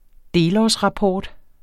Udtale [ ˈdeːlˌɒˀs- ]